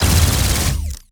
Added more sound effects.
GUNAuto_Plasmid Machinegun C Burst Unstable_02_SFRMS_SCIWPNS.wav